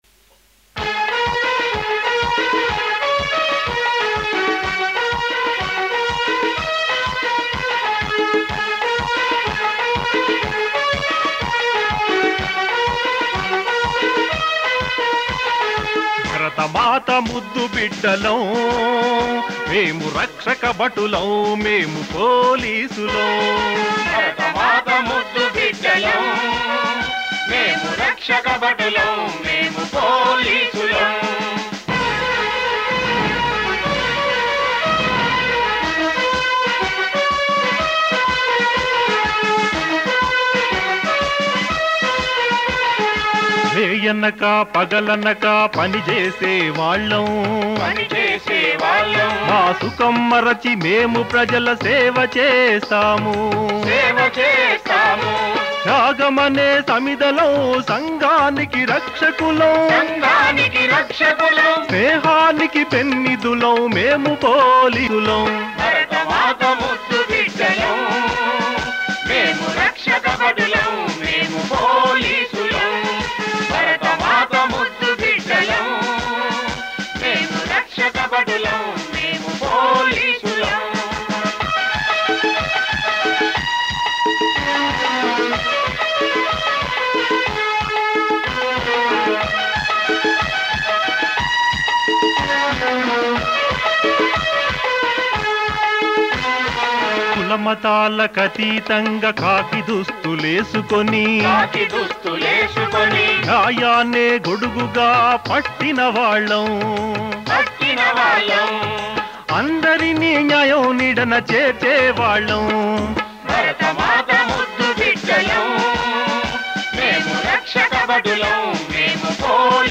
Several songs were written, recorded and performed among the people.